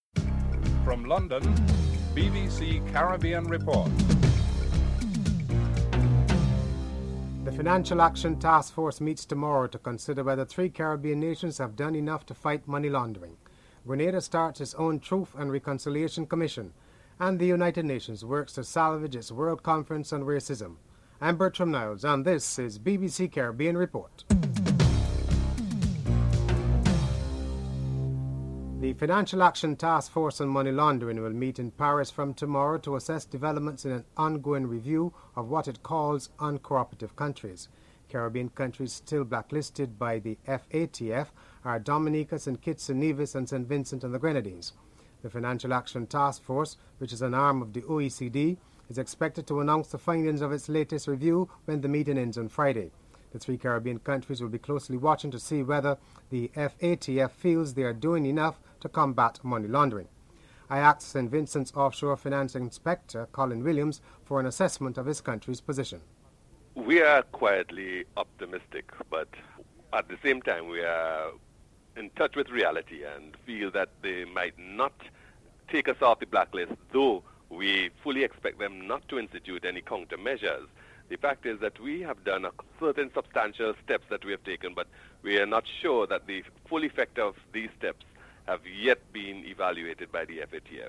1. Headlines (00:00-00:26)
5. United Nations works to salvage its World Conference on Racism. South Africa Foreign Minister Nkosazana Dlamini-Zuma is interviewed (08:38-09:54)